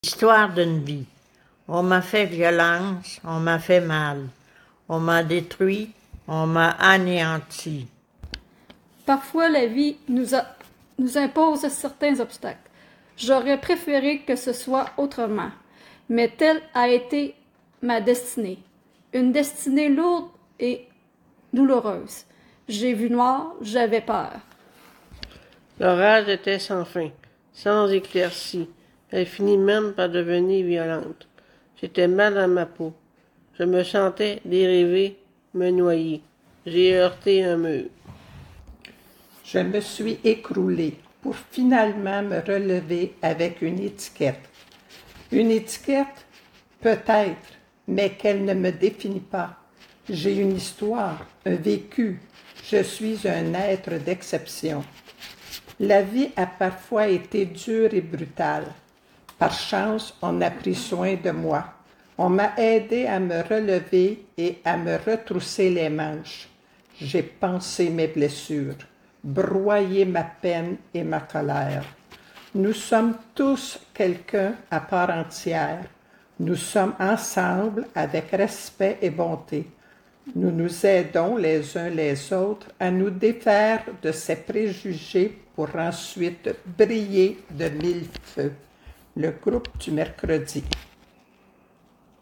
– Lecture par le groupe du mercredi